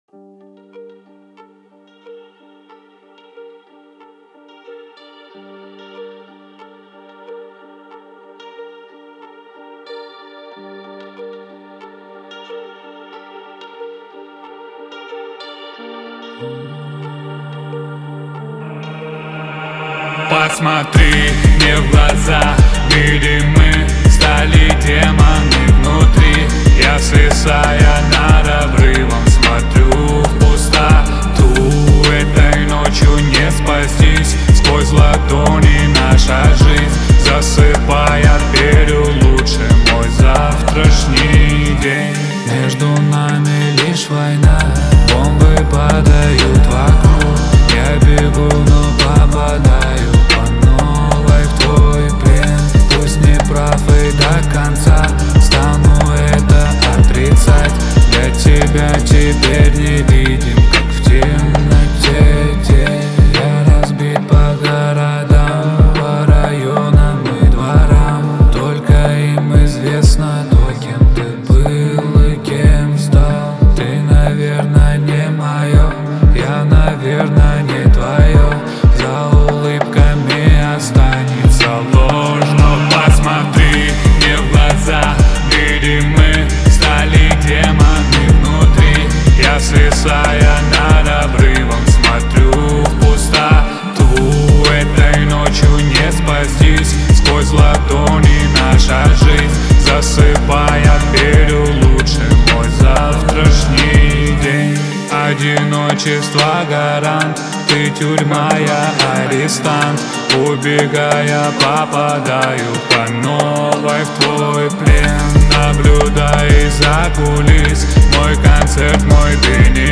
Мощные гитарные рифы